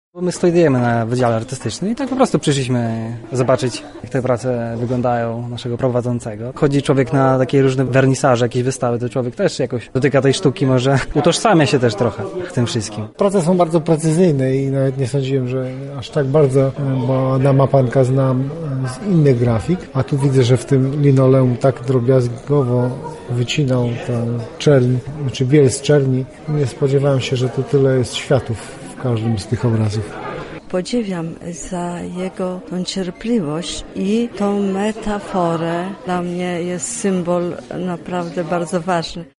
Odczuciami na temat wernisażu podzielili się oglądający: